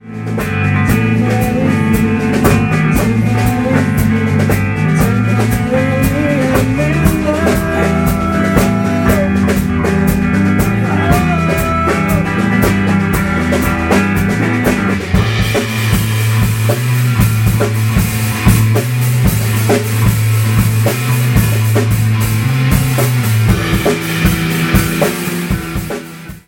Diktafon sony a hodně nepovedená zkouška